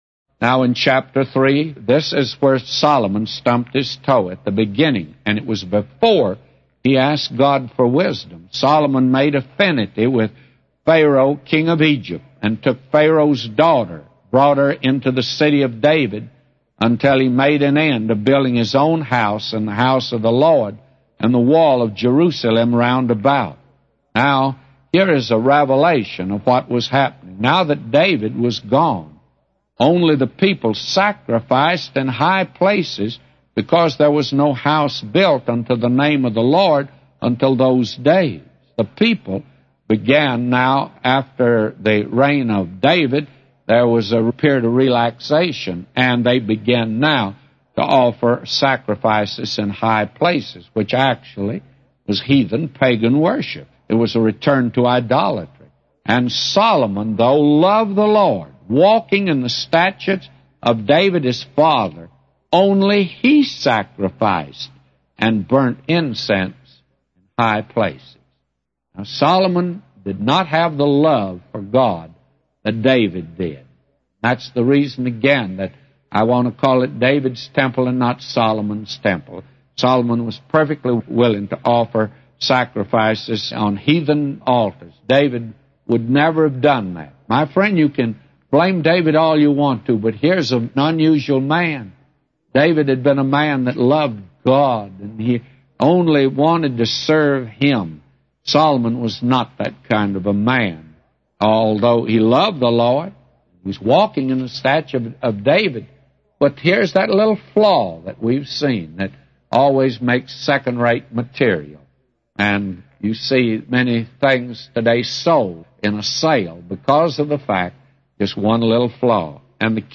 A Commentary By J Vernon MCgee For 1 Kings 3:1-999